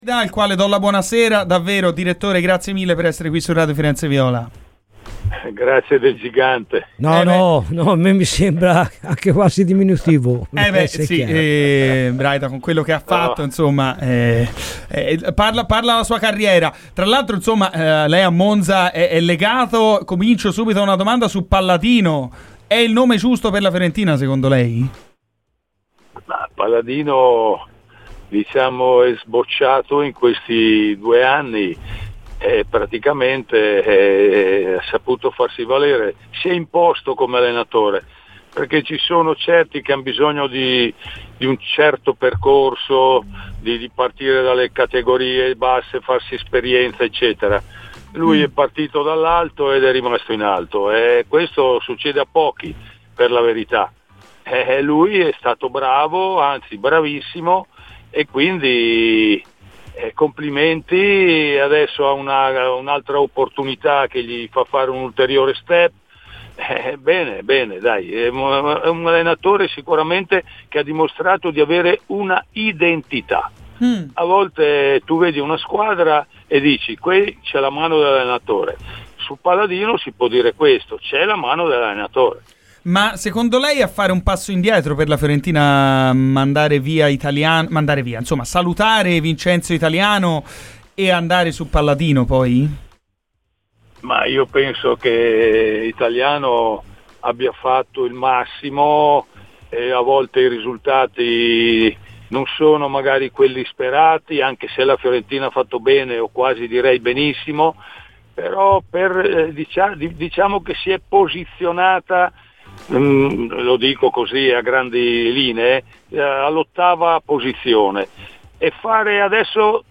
L'ex dirigente del Milan Ariedo Braida ha parlato oggi a Radio Firenzeviola.